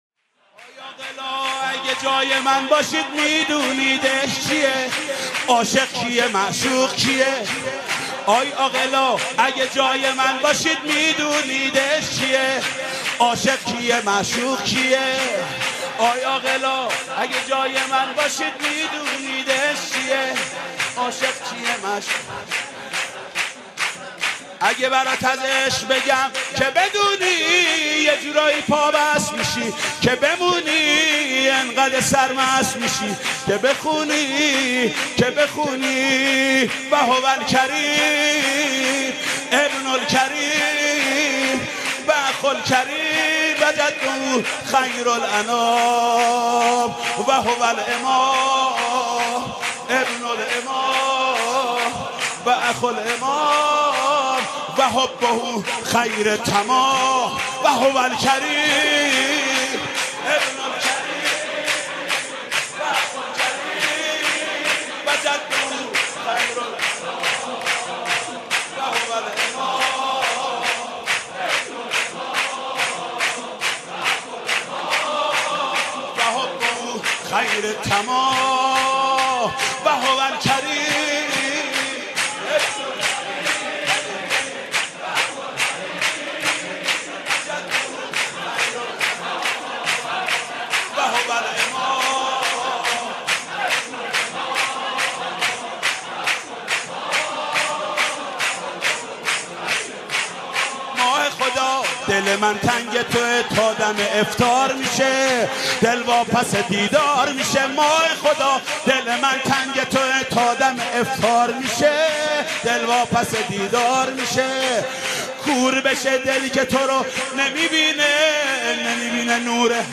سرود: آی عاقلا اگه جای من باشی میدونید عشق چیه